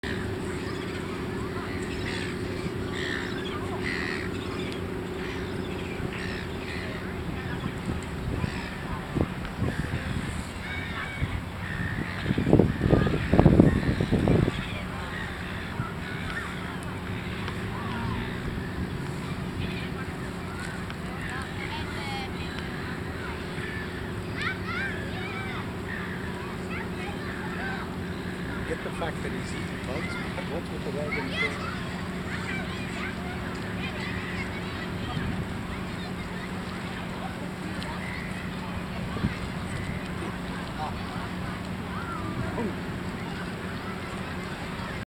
Willy Wagtail Bird